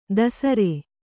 ♪ dasare